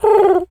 pigeon_call_calm_06.wav